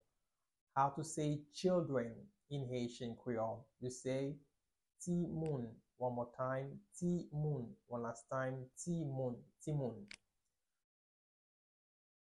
Pronunciation:
1.How-to-say-Children-in-haitian-creole-–-Timoun-pronunciation-.mp3